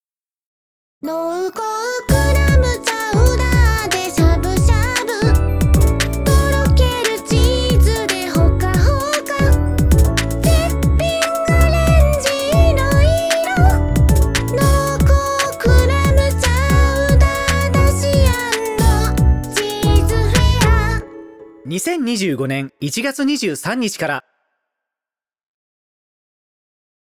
楽曲CM